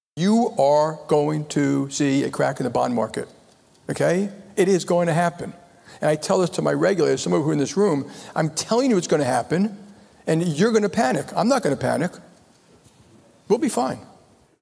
Dimon_BondMarket_ReaganConf_01.mp3